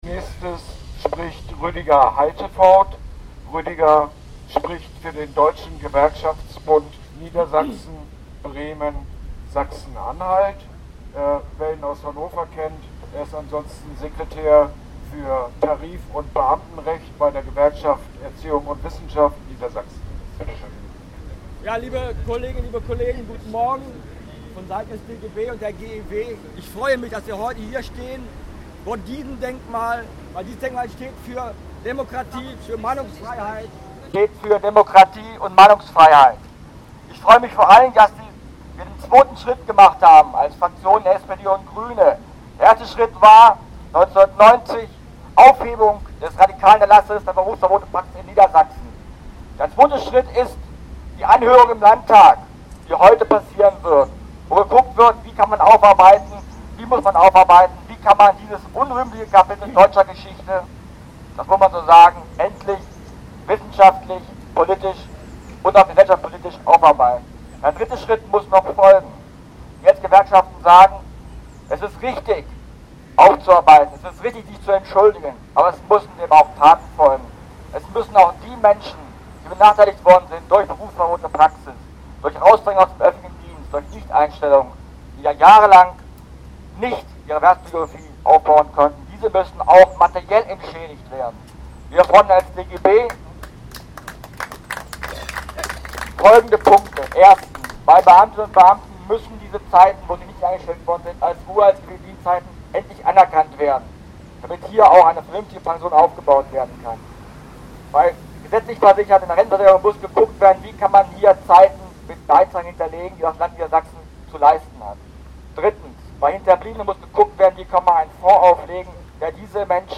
Kundgebung am Denkmal der wegen ihres Protests gegen Verfassungsbruch in früheren Zeiten mit Berufsverbot belegtenGöttinger Sieben“ vor dem Niedersächsischen Landtag.